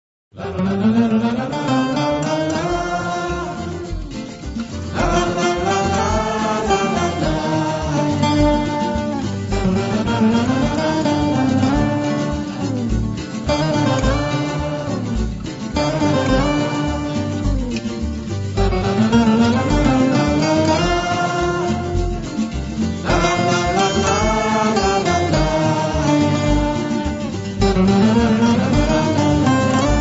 lead vocals